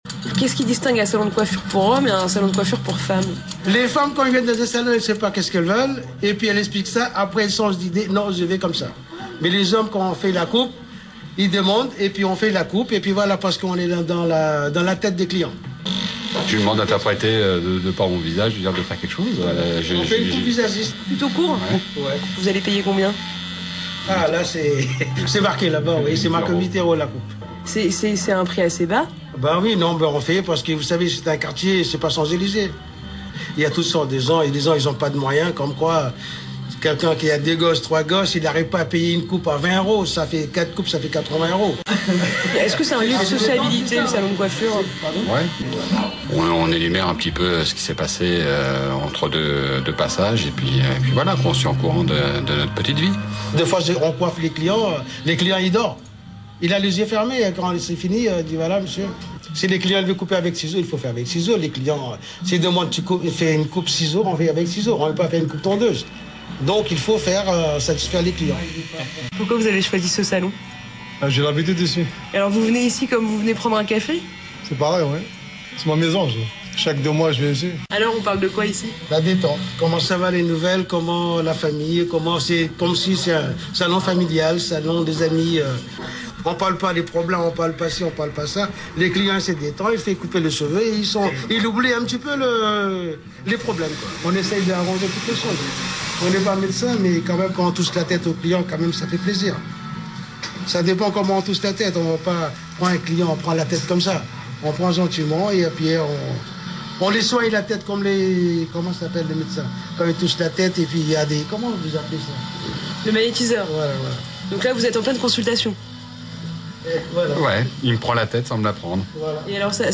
Mais apparemment, les hommes aussi racontent leur vie en se faisant couper les cheveux. Echange sympathique entendu à la radio entre un coiffeur originaire de l’autre côté de la Méditerranée et un habitué de ce salon convivial d’un quartier populaire.
coiffeur-pour-hommes.mp3